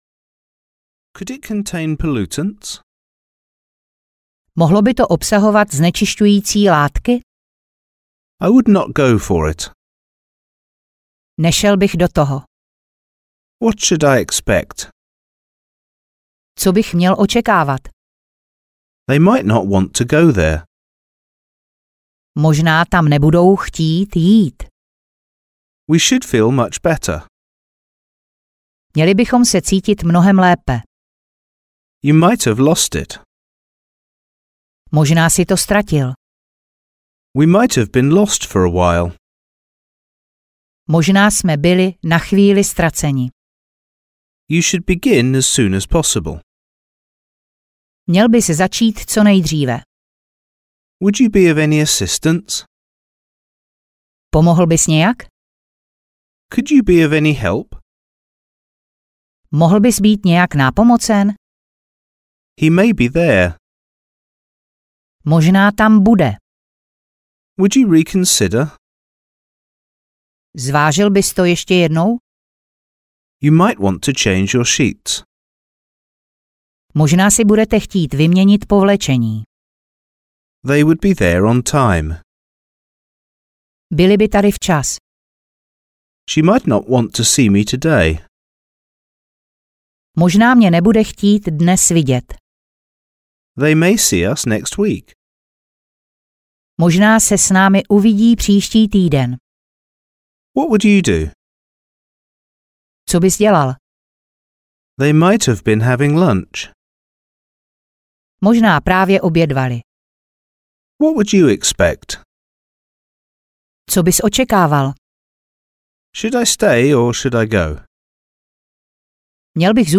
Anglická gramatika B2 audiokniha
Ukázka z knihy